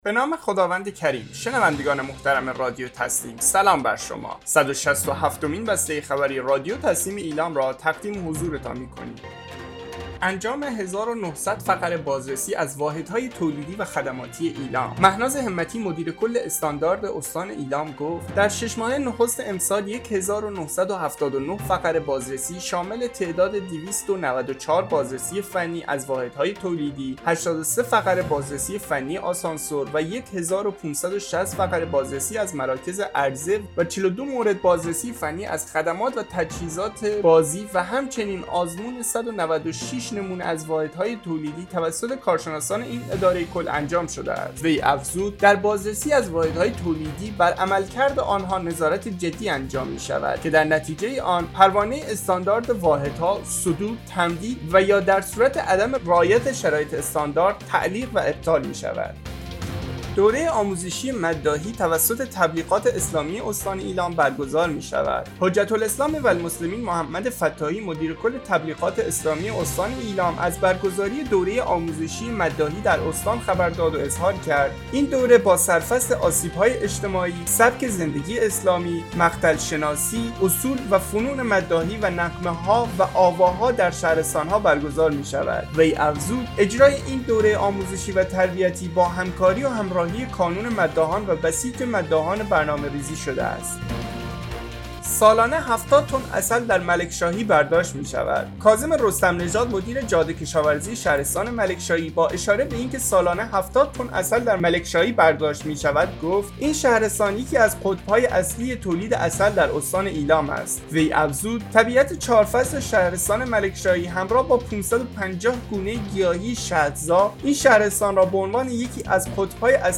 به گزارش خبرگزاری تسنیم از ایلام، صد و شصت و هفتمین بسته خبری رادیو تسنیم استان ایلام باخبرهایی انجام 1900 فقره بازرسی از واحدهای تولیدی و خدماتی ایلام، دوره آموزشی مداحی توسط تبلیغات اسلامی استان ایلام برگزار می‌شود و سالانه 70 تن عسل در«ملکشاهی» برداشت می‌شود، منتشر شد.